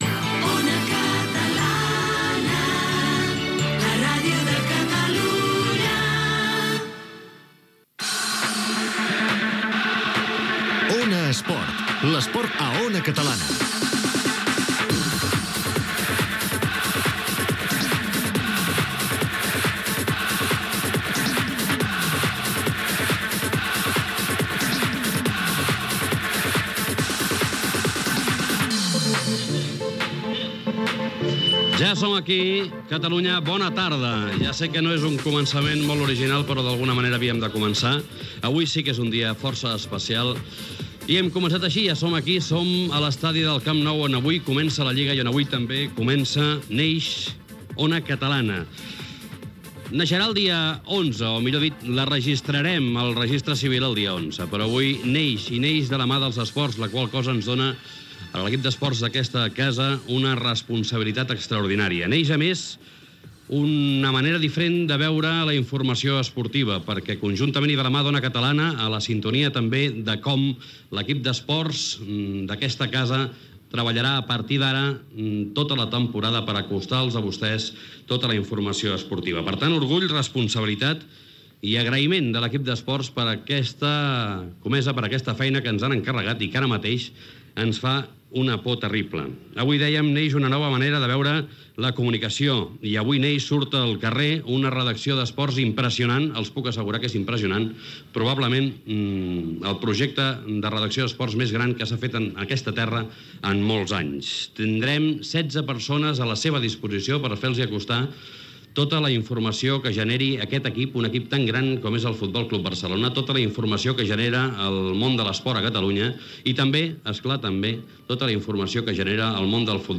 Emissió inaugural. Indicatiu de l'emissora, careta del programa, primera transmissió de futbol masculí d'Ona Catalana des de l'estadi del Futbol Club Barcelona. Juguen el F.C. Barcelona i el Màlaga.
Esportiu